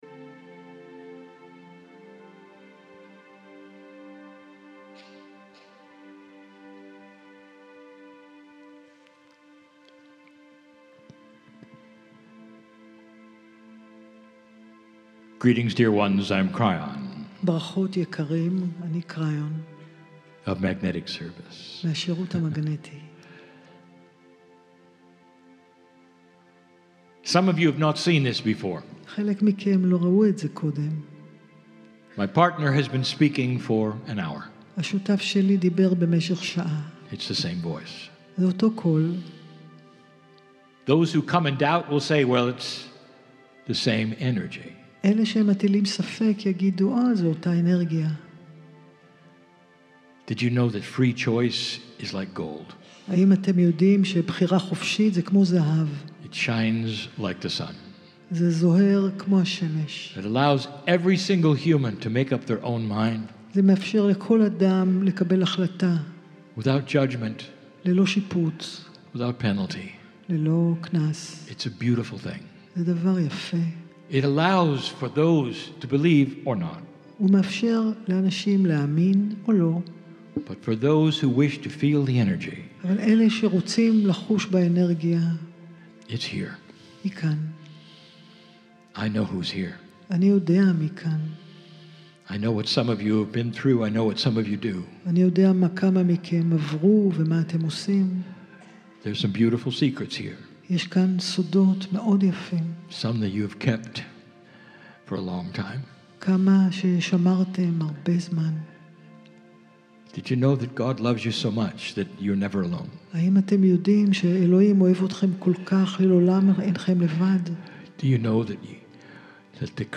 KRYON CHANNELLING OPERA HOUSE 1